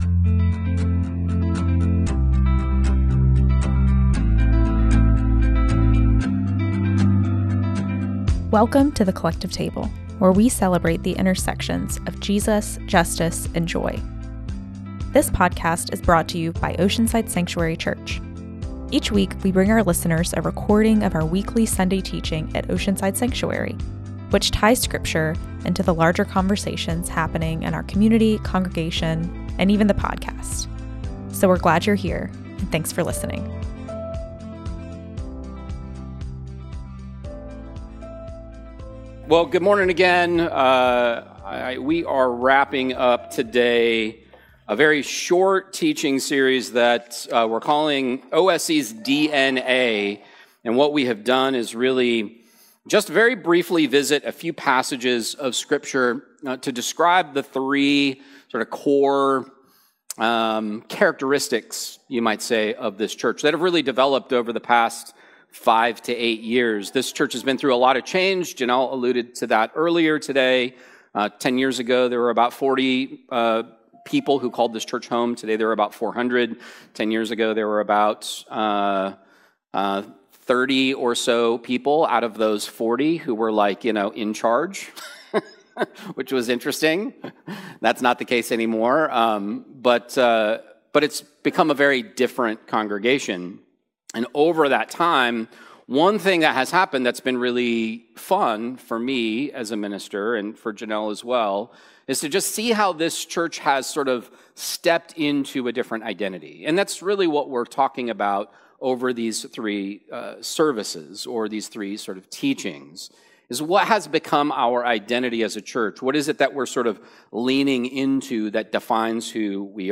OSC Sunday Teaching - "Hungry Strangers" - June 29th, 2025
This teaching was recorded on Sunday, June 29th, 2025 at The Oceanside Sanctuary Church (OSC) in Oceanside, CA.